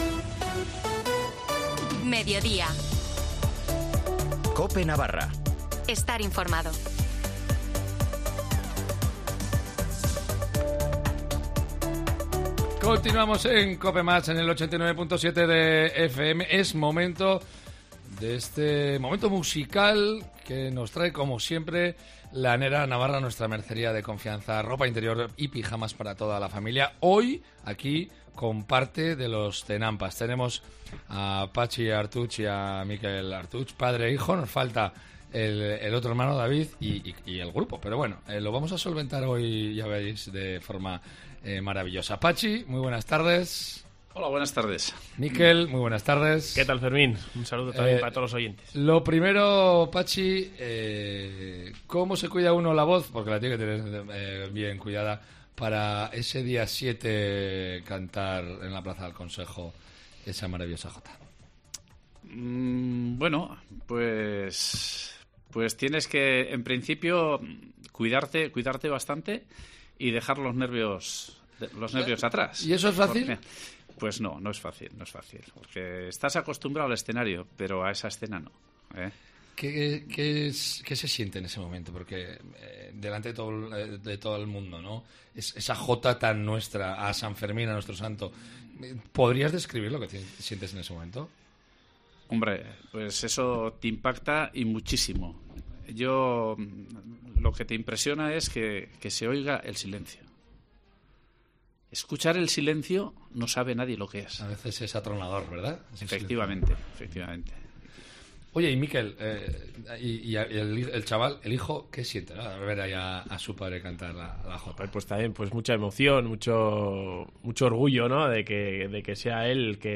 cantan en directo en Cope Navarra.